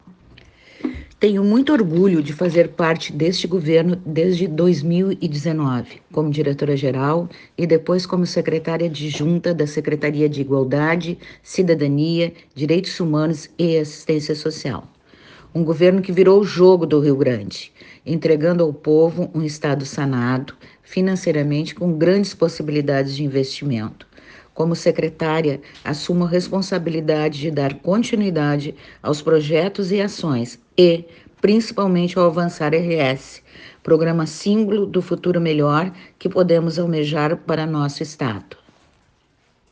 Secretária Márcia de la Torre
A secretária Márcia falou da responsabilidade em dar continuidade aos trabalhos na SICDHAS.